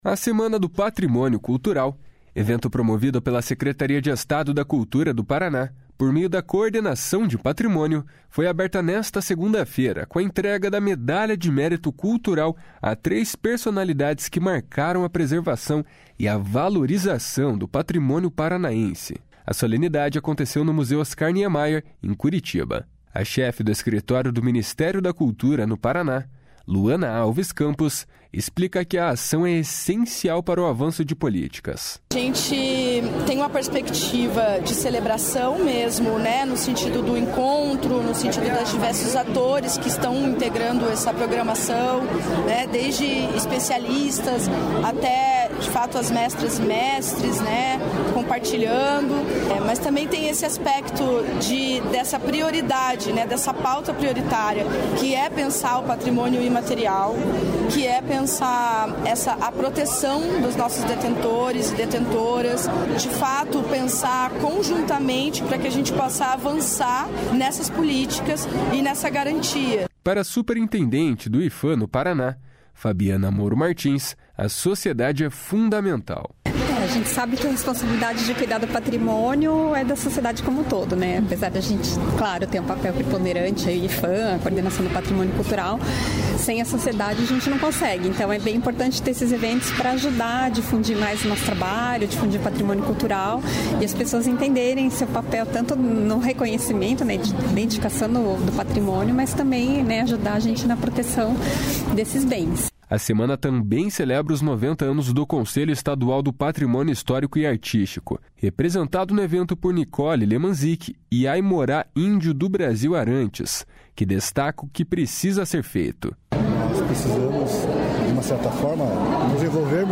A solenidade aconteceu no Museu Oscar Niemeyer, em Curitiba.